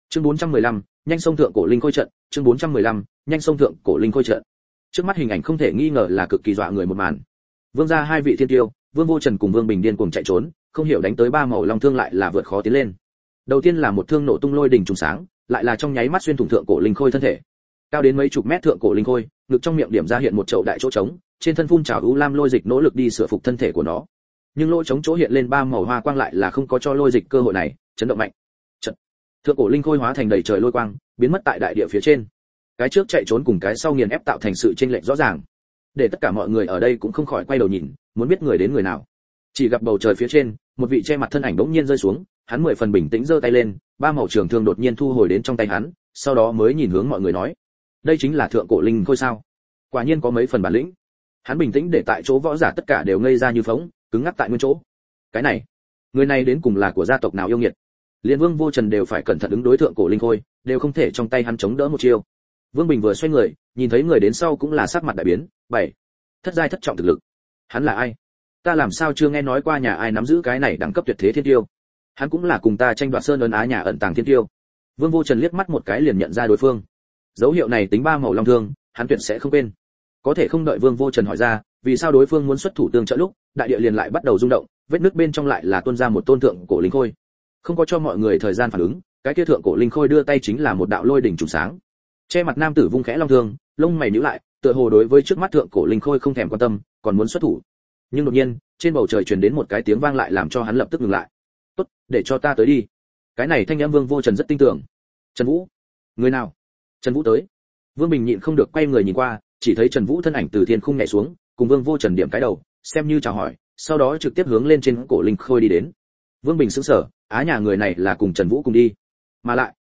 Cao Võ: Khắc Kim Thêm Điểm, Ngươi Toàn Thêm Thể Phách? Audio - Nghe đọc Truyện Audio Online Hay Trên RADIO TRUYỆN FULL